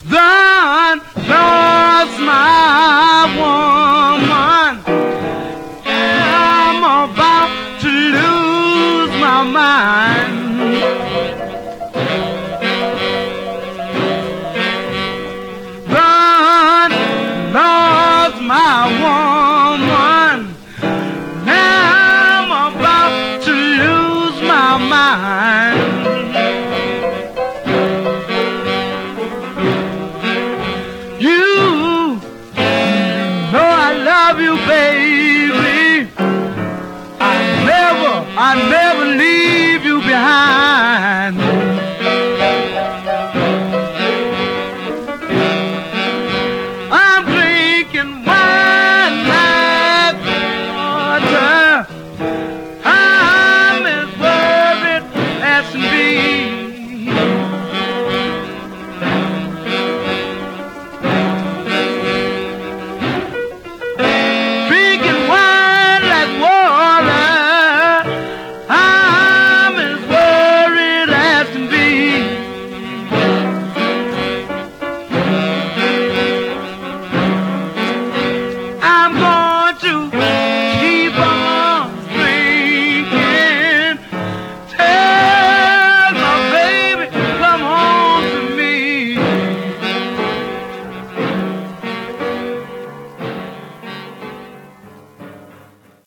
Some surface noise/wear
Mono
Blues